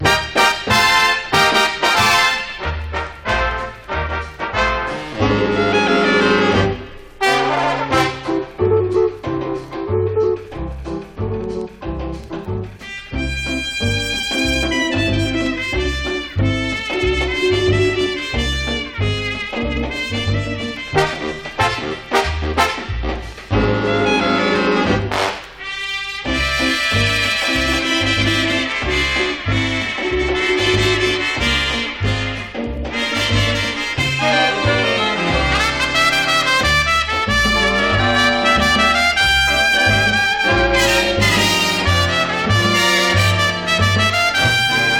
Jazz, Big Band, Cool Jazz　USA　12inchレコード　33rpm　Mono